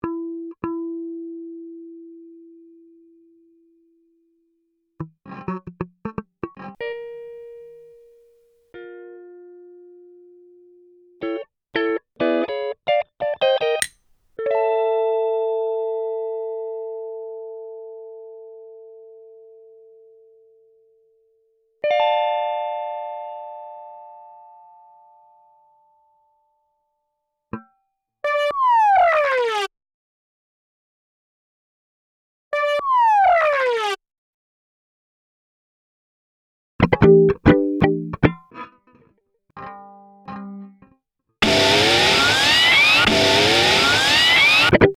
Buttons_Slides_Dings_and_Drops_Stereo.mp3